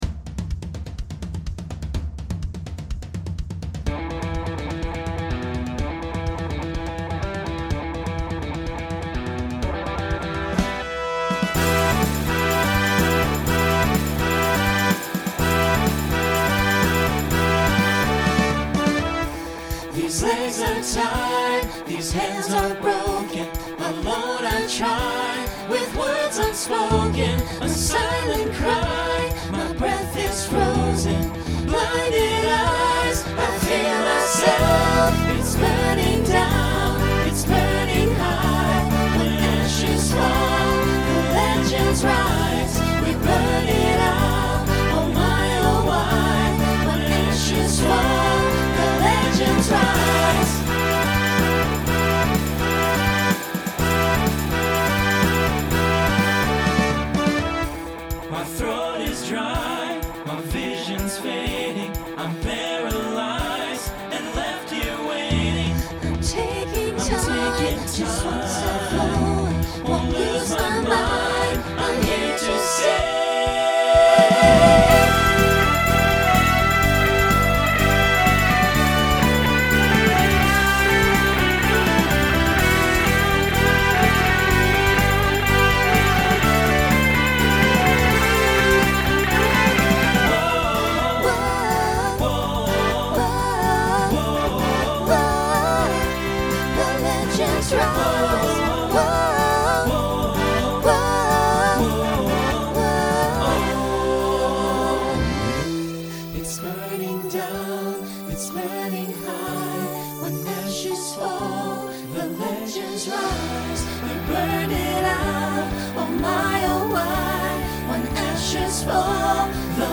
New TTB voicing for 2020